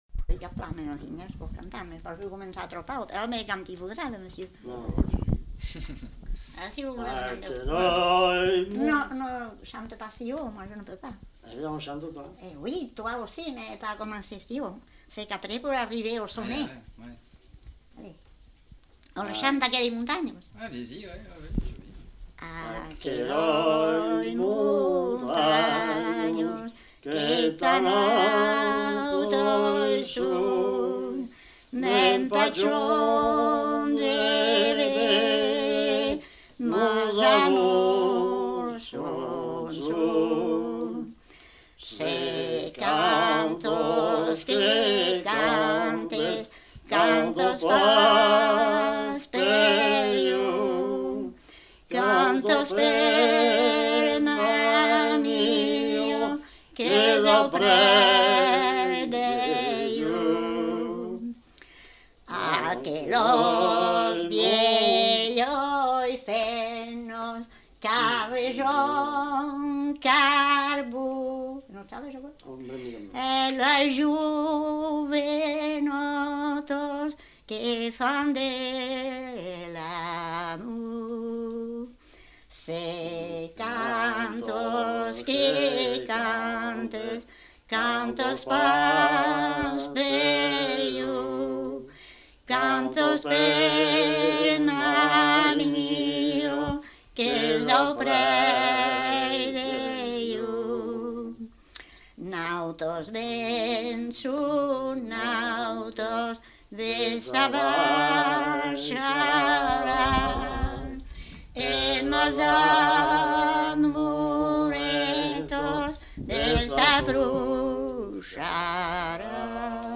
Aire culturelle : Couserans
Lieu : Pause-de-Saut (lieu-dit)
Genre : chant
Effectif : 2
Type de voix : voix d'homme ; voix de femme
Production du son : chanté
Descripteurs : polyphonie